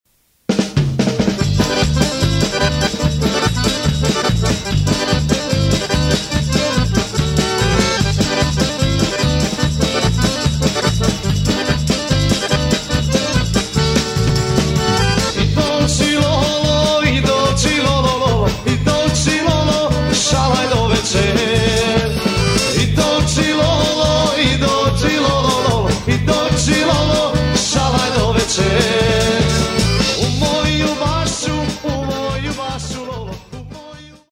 Mixano u "Profile Studios" Vancouver BC
u "Slanina Studios" Vancouver BC...